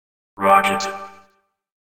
rocket_bonus.ogg